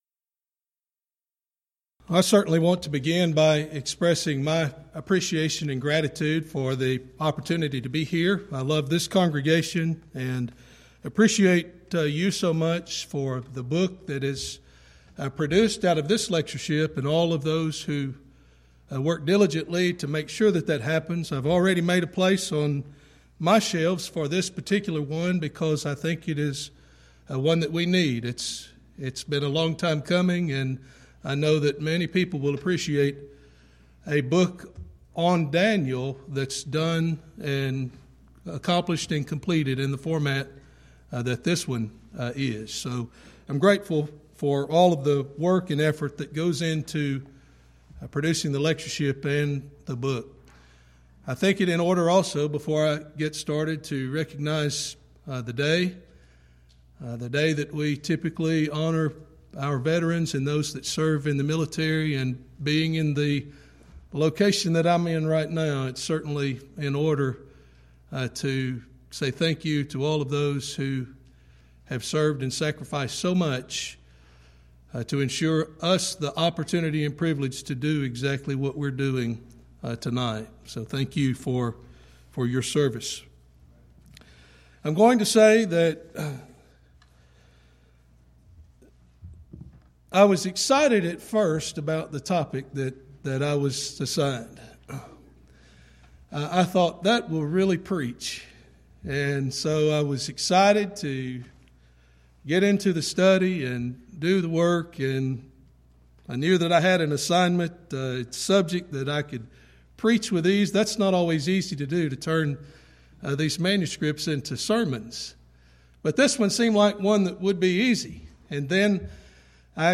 Event: 11th Annual Schertz Lectures Theme/Title: Studies in Daniel